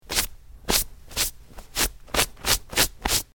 Cloth, Wipe
Brushing Off Clothes With Hands, X6